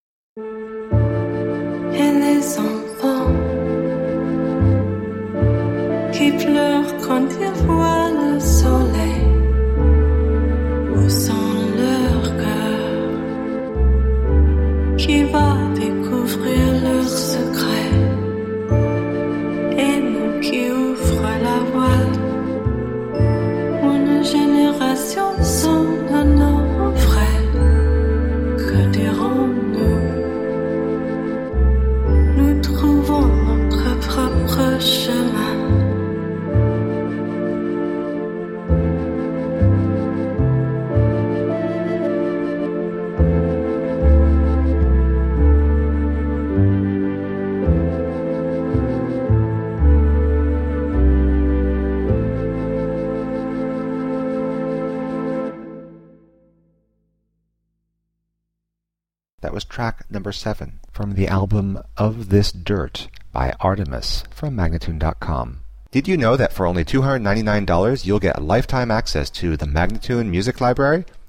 Alternative / downtempo / electro-pop.